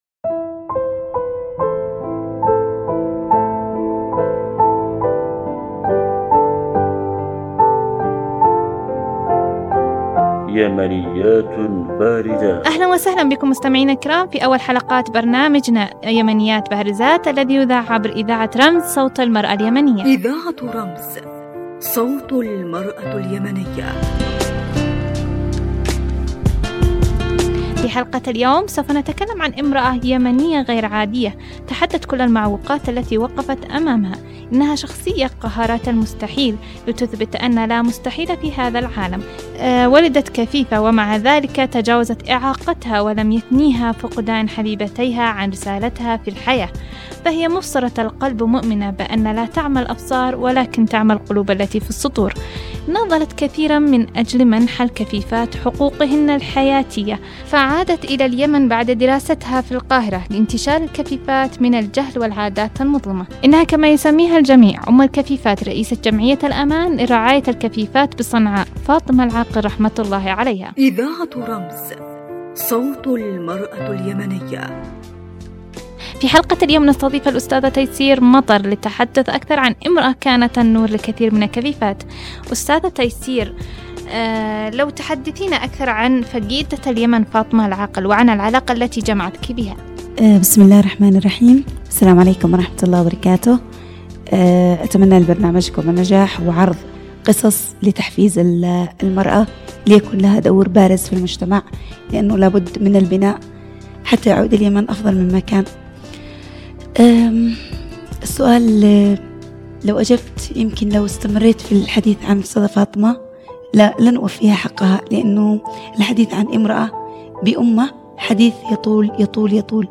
برنامج أسبوعي يستضيف عدد من اليمنيات للحديث عن شخصيات بارزة
هنا في استوديو اذاعة رمز